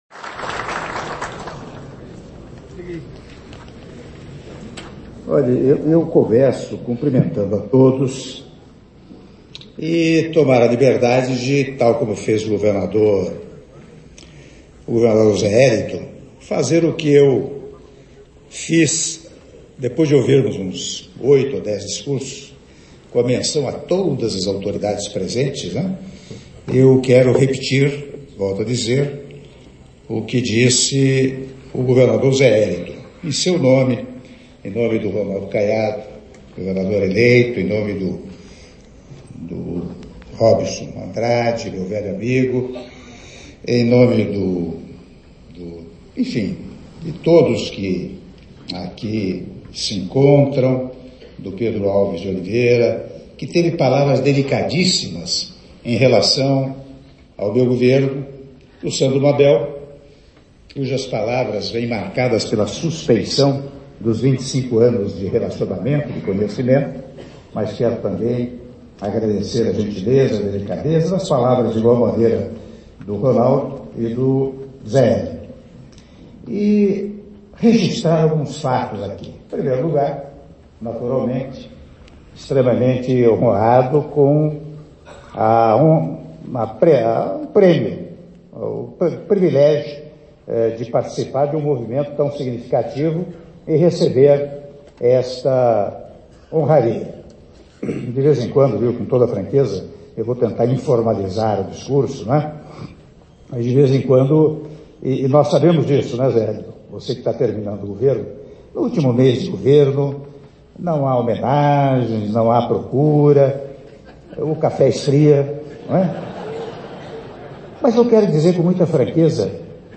Áudio do discurso do Presidente da República, Michel Temer, durante cerimônia de posse da nova diretoria da Federação das Indústrias do Estado de Goiás - Goiânia/GO (21min10s)